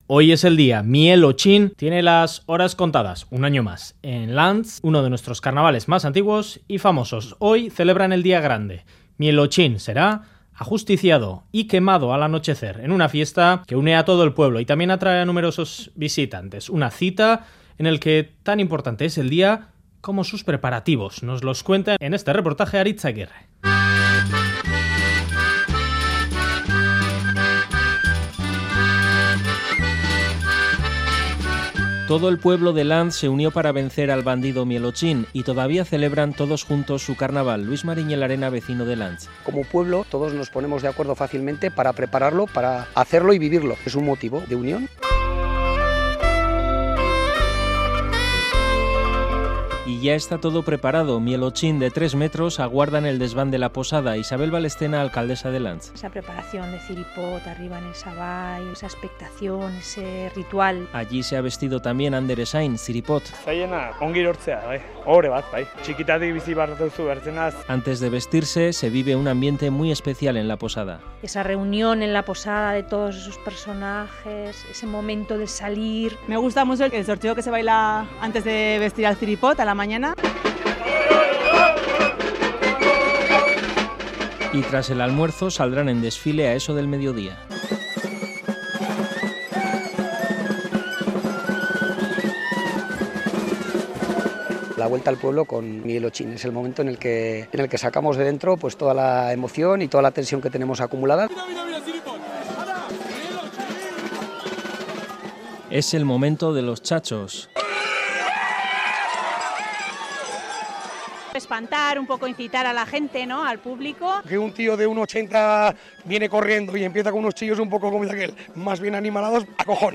Radio Euskadi REPORTAJES Las horas contadas de Miel Otxin Última actualización: 14/02/2018 12:05 (UTC+1) Cuenta la leyenda que todo el pueblo de Lantz se unió para vencer al bandido Miel Otxin que los tenía amedrentados.